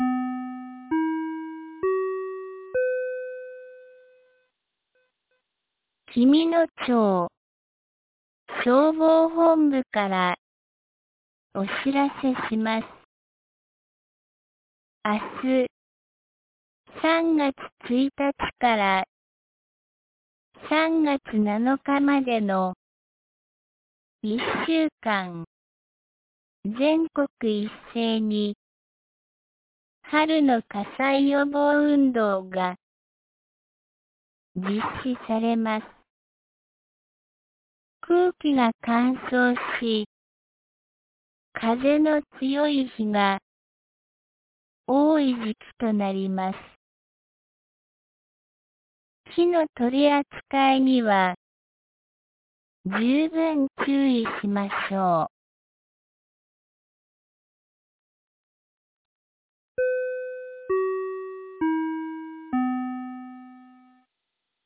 2026年02月28日 17時06分に、紀美野町より全地区へ放送がありました。